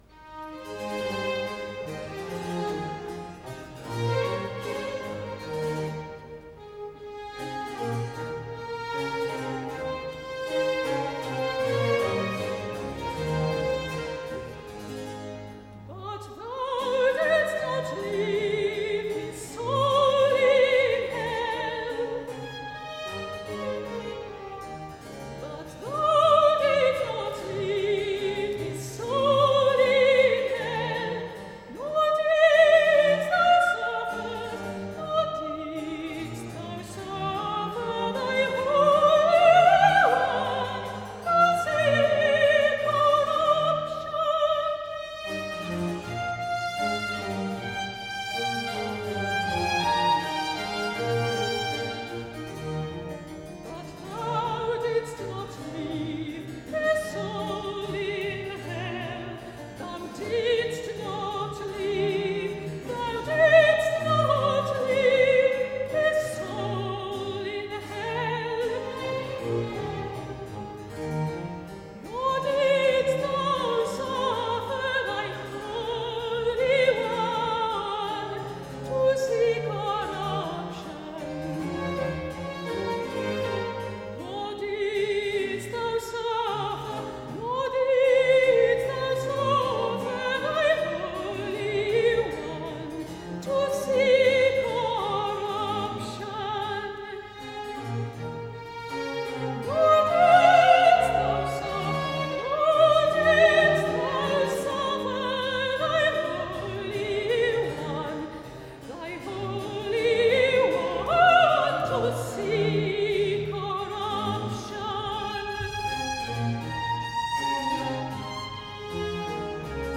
Aria-soprano